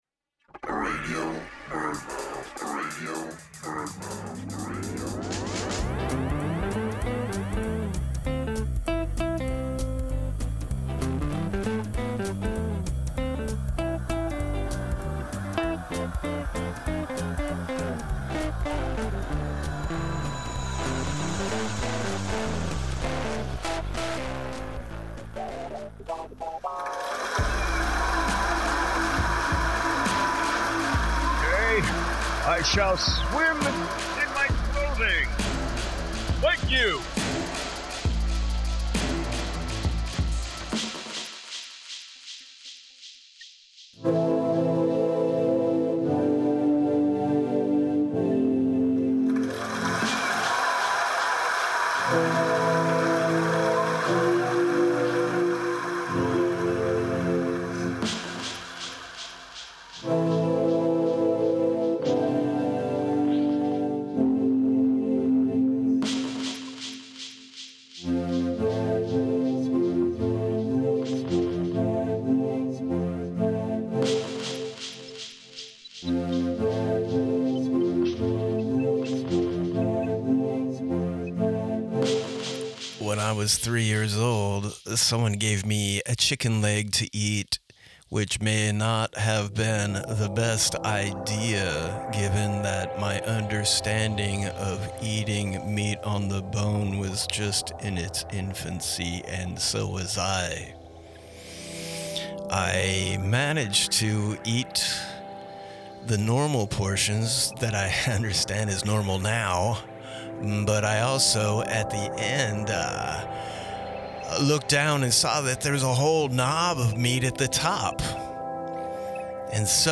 "Radio BirdMouth" is a loose-form audio road trip woven together from fragmentary spoken word narratives and sound manipulations.